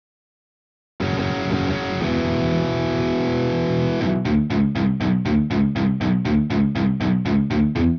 (also, please note this is intentionally without reverb)